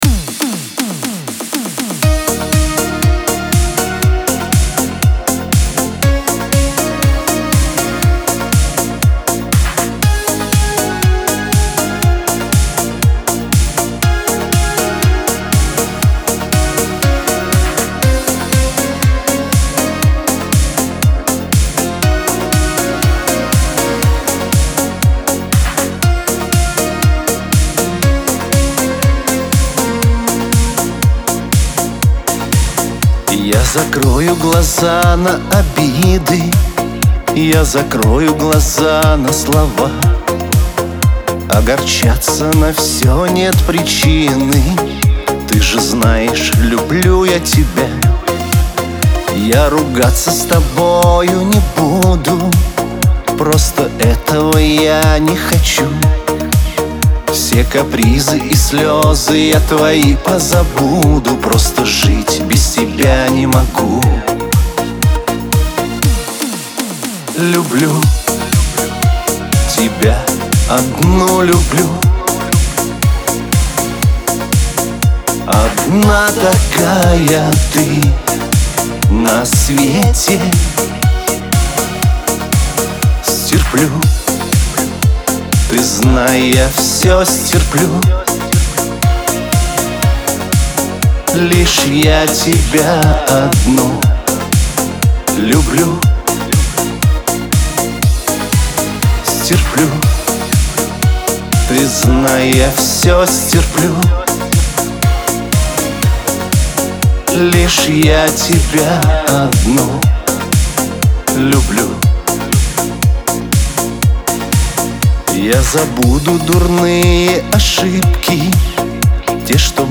диско
pop